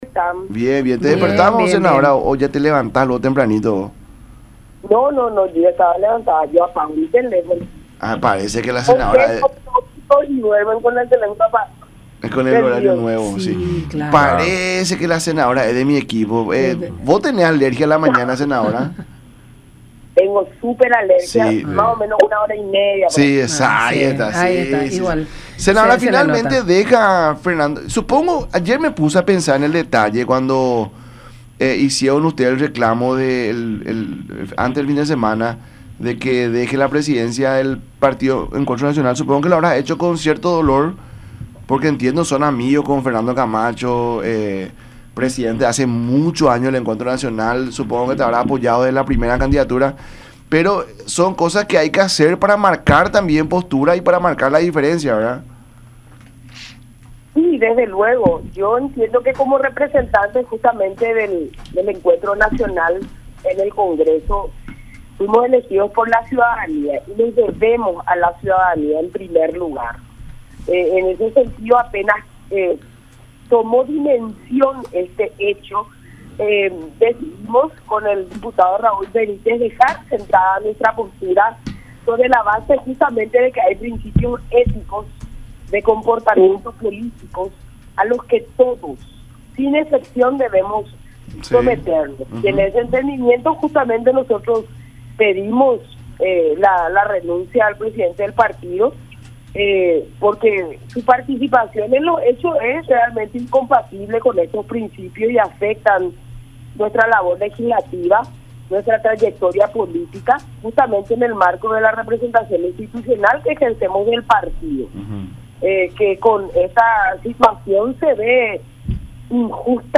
Desconocíamos este caso” , agregó en el programa “La Mañana de Unión” por radio La Unión y Unión Tv.